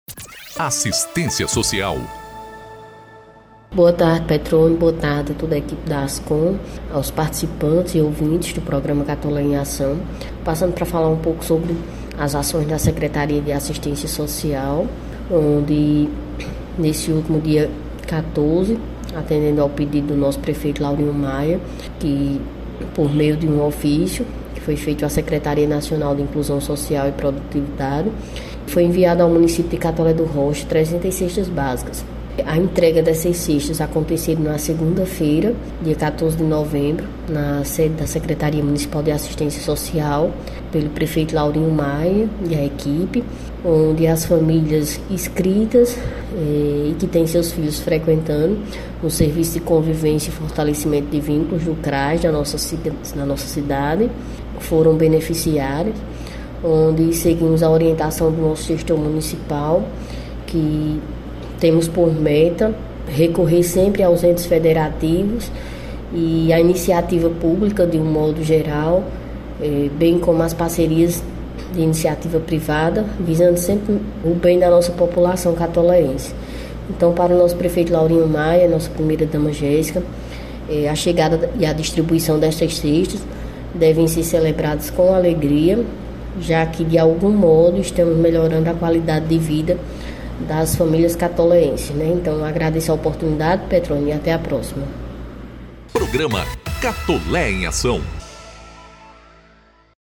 Ouça a Secretária Janaína Diniz: